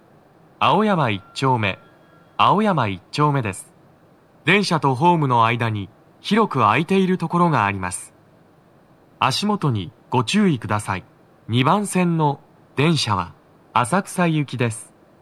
スピーカー種類 TOA天井型
足元注意喚起放送が付帯されており、多少の粘りが必要です。
到着放送1